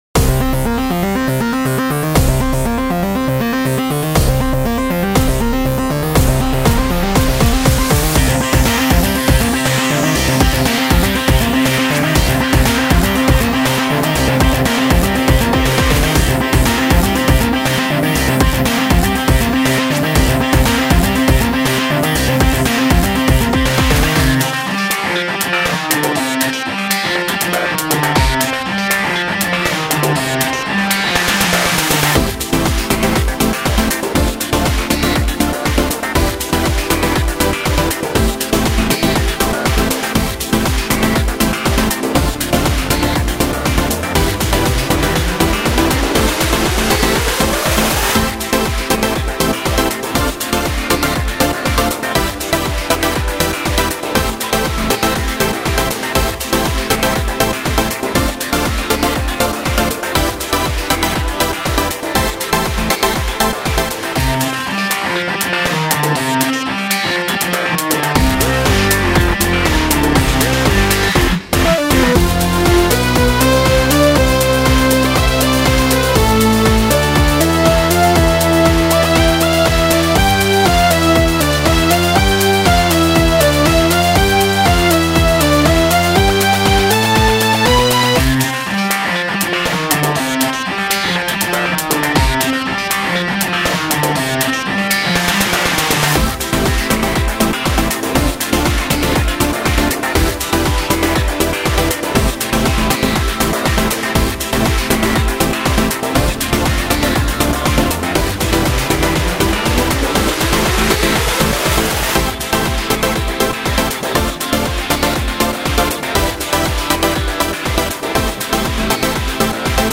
フリーBGM 戦闘曲